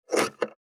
486台所,野菜切る,咀嚼音,ナイフ,調理音,
効果音厨房/台所/レストラン/kitchen食器食材